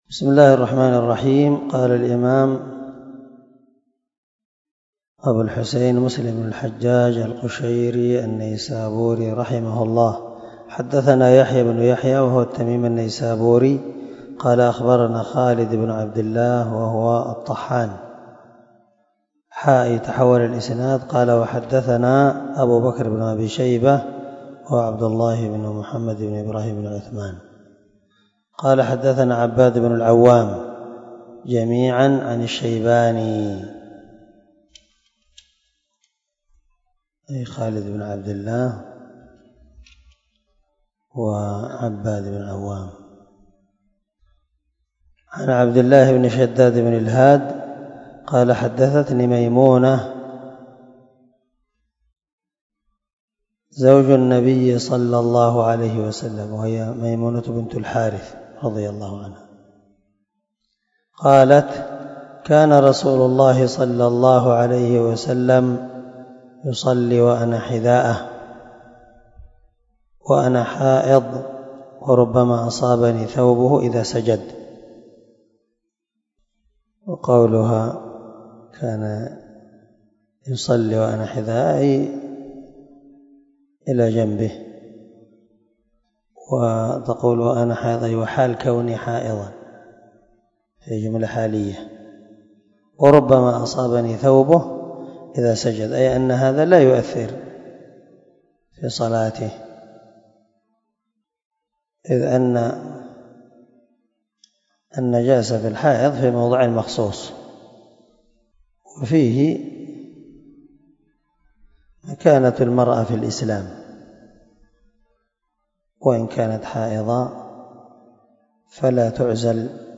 327الدرس 71 من شرح كتاب الصلاة حديث رقم ( 513 - 516 ) من صحيح مسلم